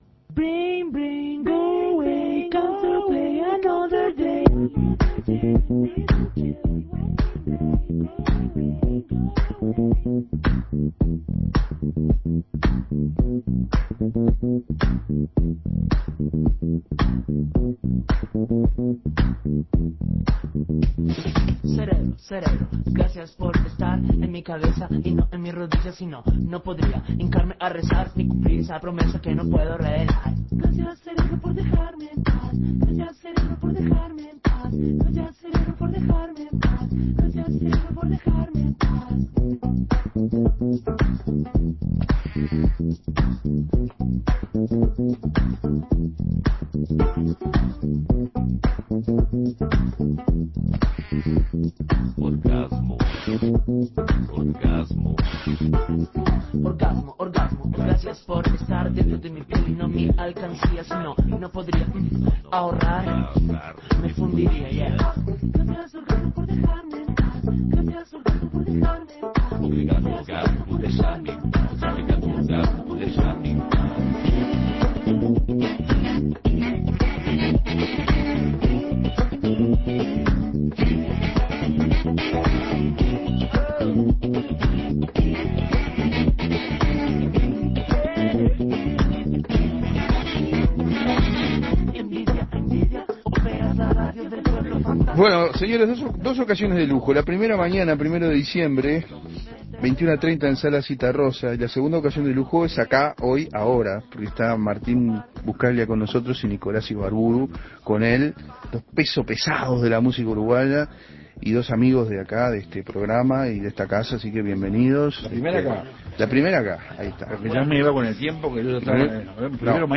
Presentó su disco, invitó a su show e hizo música en vivo.